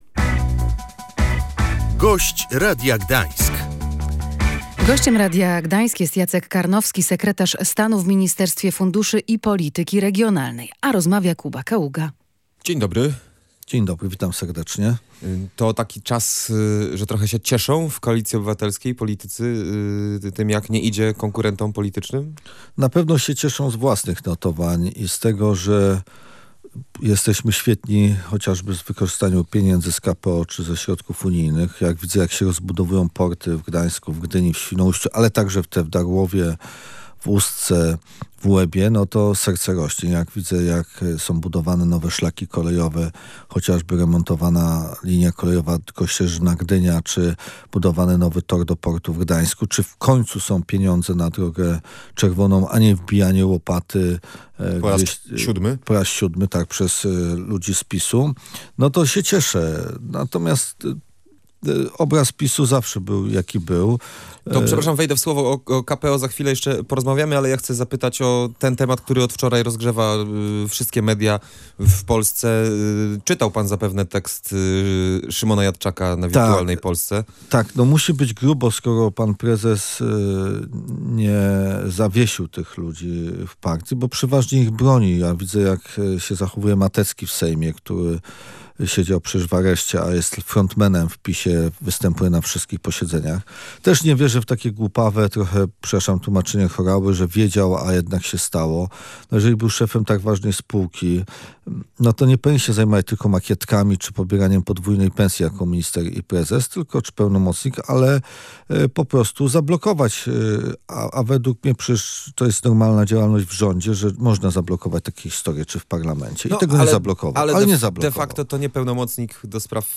To jest rzecz nieprawdopodobna, która, mam nadzieję, utopi PiS – mówił na antenie Radia Gdańsk Jacek Karnowski. Wiceminister funduszy i polityki regionalnej skomentował sprawę kontrowersyjnej sprzedaży działki strategicznej dla realizacji Centralnego Portu Komunikacyjnego.
Gość Radia Gdańsk